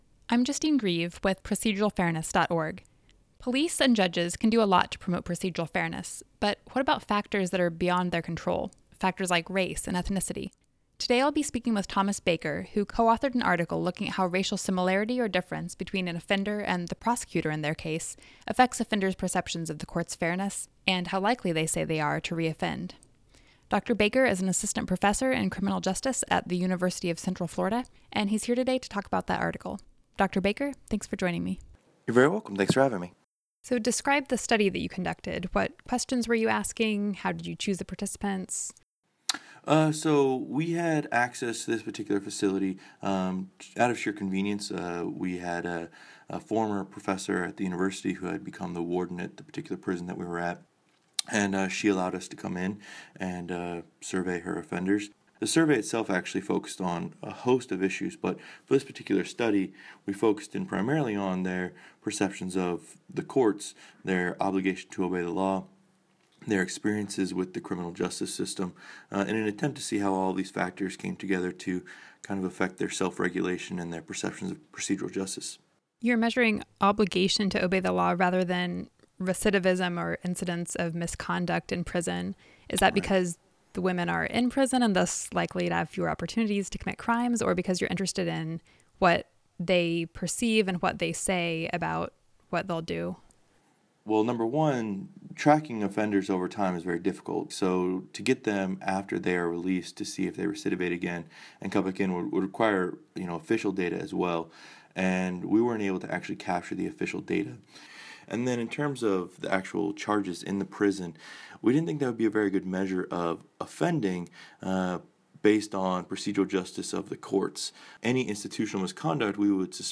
Edited Interview (5:28)